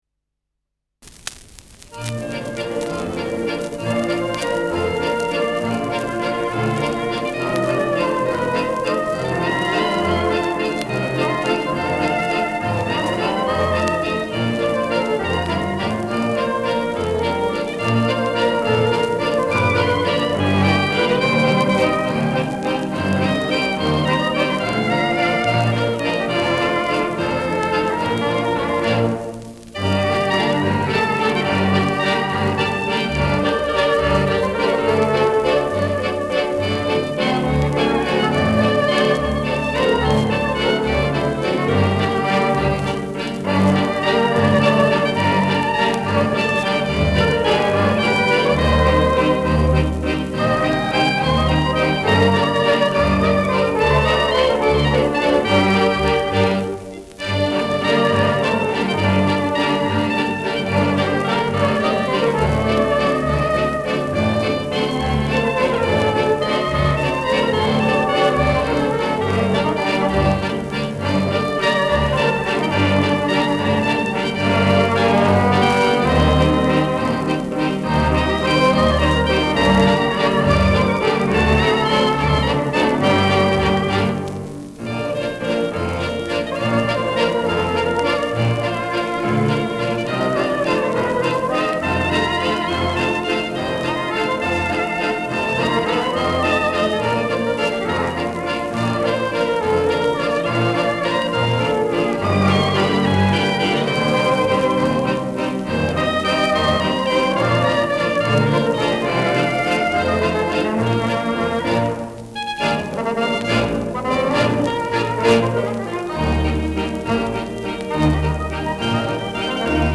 Schellackplatte
Tonrille: Abrieb : Kratzer 12 / 5 Uhr
[Zürich] (Aufnahmeort)
Ländlerkapelle* FVS-00018